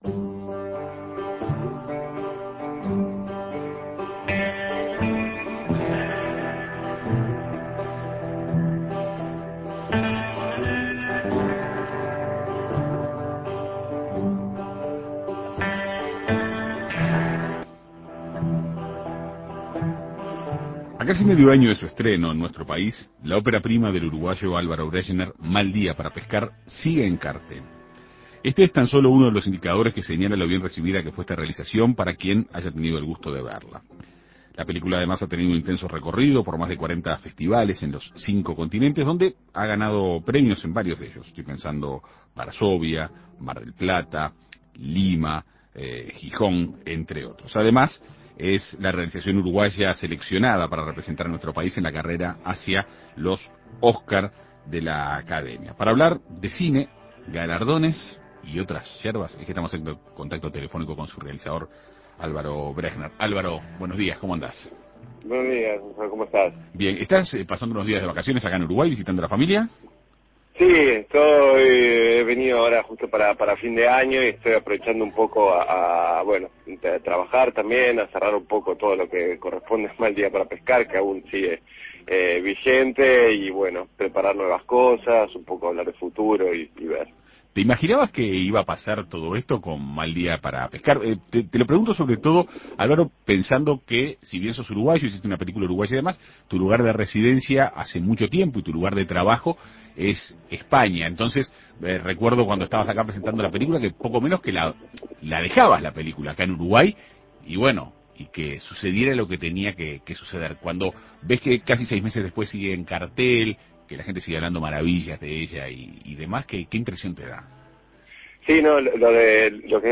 Además, el filme fue seleccionado para representar a Uruguay en la carrera hacia el Oscar de la Academia a la mejor película en lengua extranjera. En Perspectiva Segunda Mañana dialogó con su director, Álvaro Brechner.